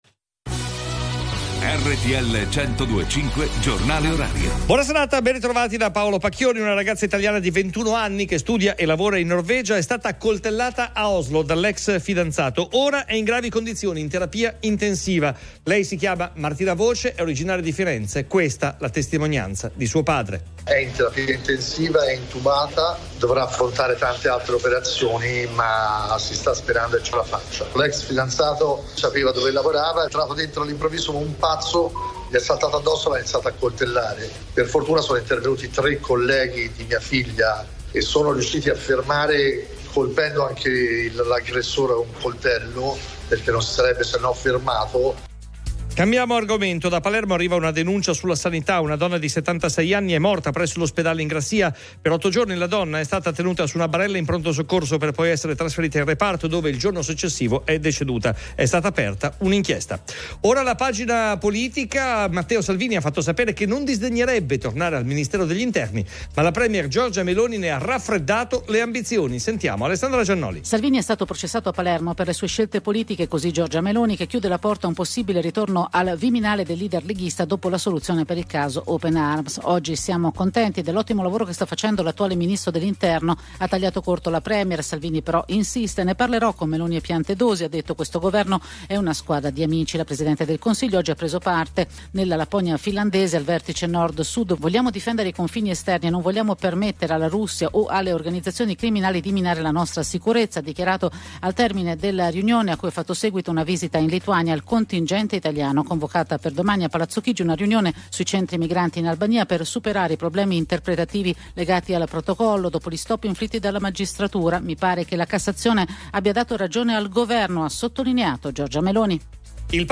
Il giornale orario di RTL 102.5 a cura della redazione giornalistica